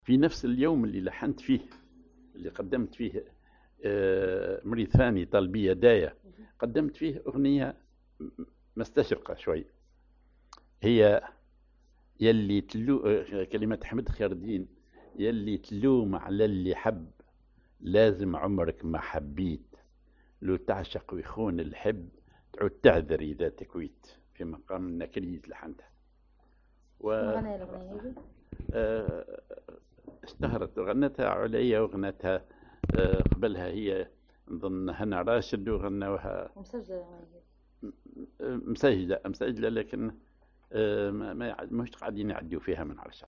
Maqam ar النكريز
Rhythm ar الوحدة